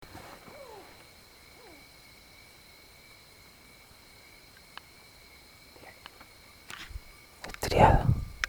Mottled Owl (Strix virgata)
Se escucharon varios ejemplares durante una recorrida nocturna pudiendo gravar dos vocalizaciones distintas.
Location or protected area: Parque Nacional Iguazú
Condition: Wild
Certainty: Recorded vocal